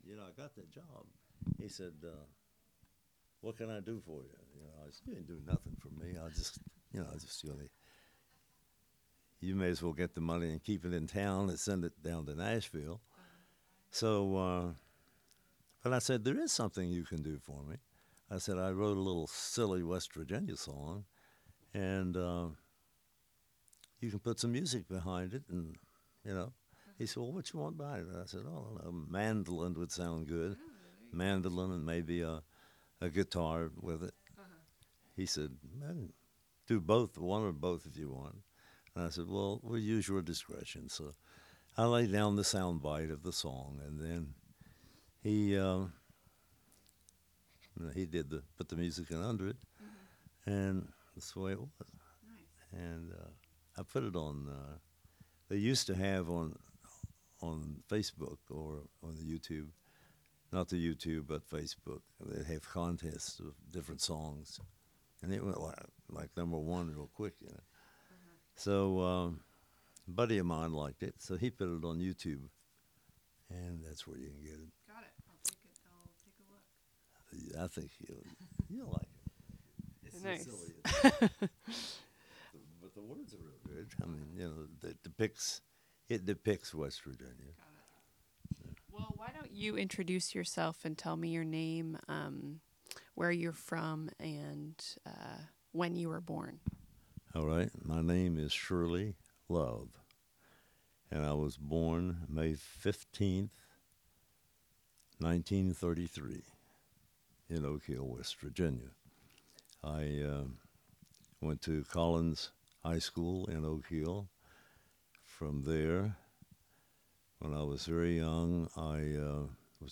Oral history of Shirley Love, 4 of 5